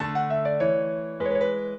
minuet6-12.wav